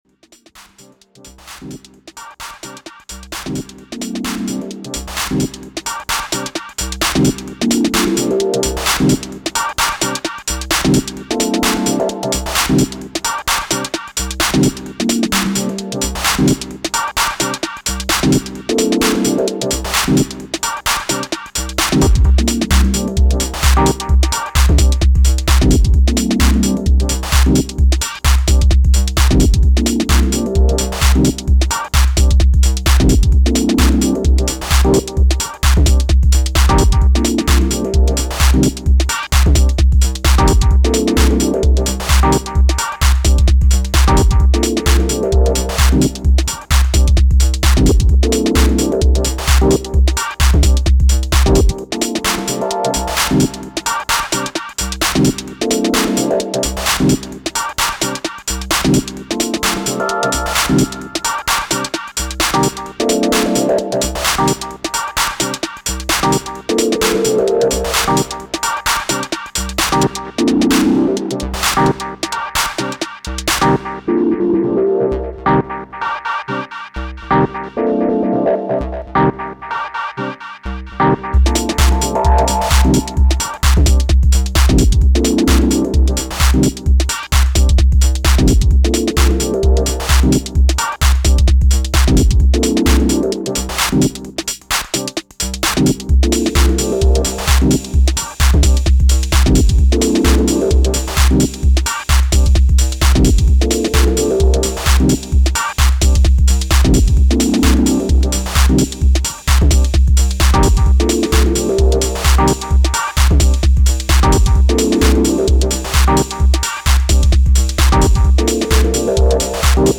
HH lab, raw, 626 conga
That is a sample of a 626 conga with a little Rytm bit reduction and an exponential lfo on the amp. i also used resonant filter pings for both the clave and tom voices